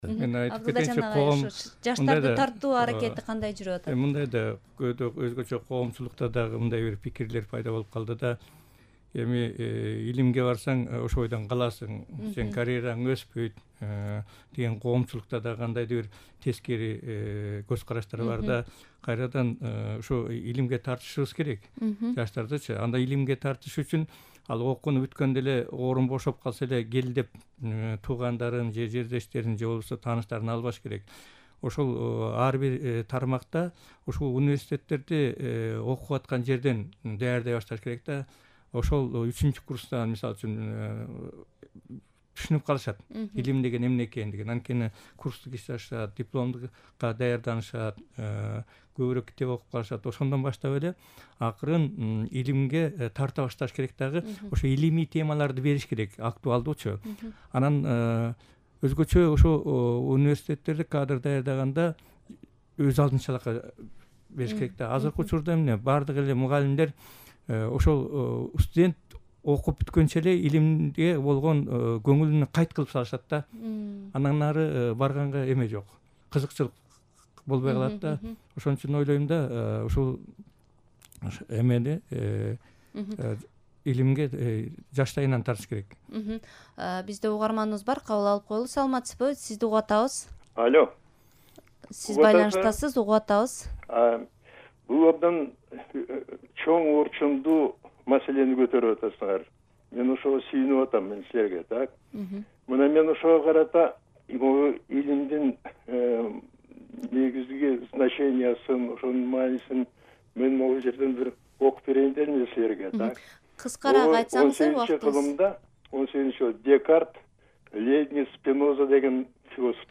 Embed бөлүшүү Илим тууралуу талкуу (2-бөлүк) автор Азаттык Үналгысы | Кыргызстан: видео, фото, кабарлар Embed бөлүшүү The code has been copied to your clipboard.